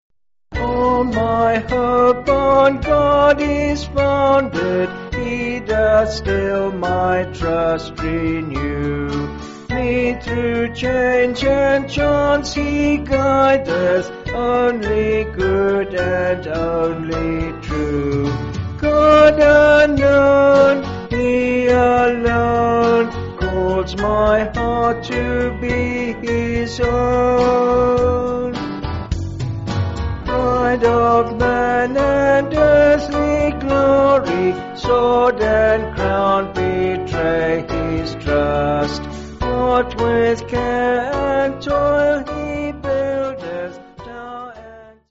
(BH)   5/Bm
Vocals and Band